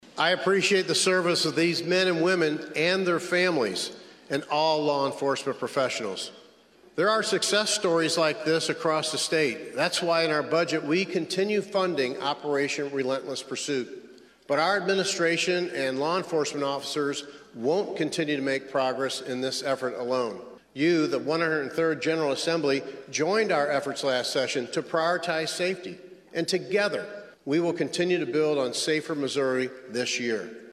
Jefferson City, Mo. (KFMO) - Missouri Governor Mike Kehoe highlighted the success of Operation Relentless Pursuit during his State of the State address Tuesday, announcing that officers arrested 1,113 criminals statewide in 2025.